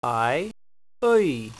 • the uh and ee blend (pronounced "uhee" like spice) (Mpg)